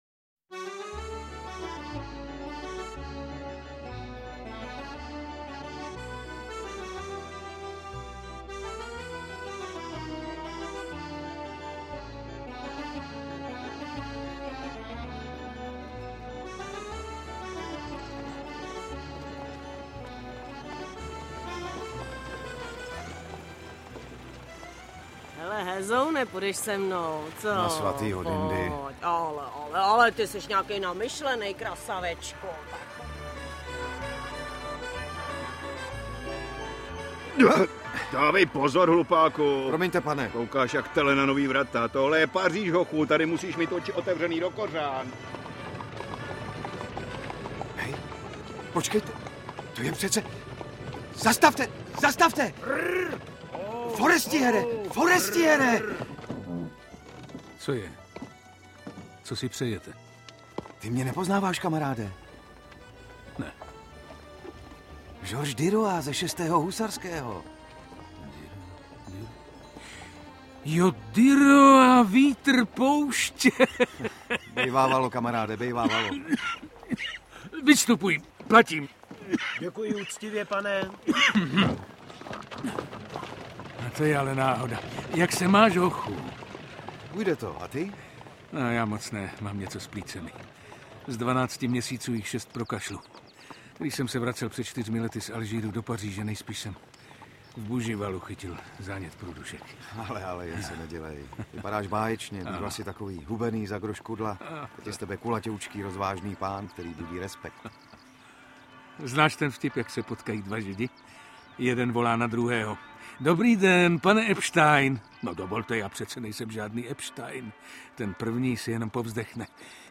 Interpret:  Jan Šťastný
Rozhlasová dramatizace z roku 2005.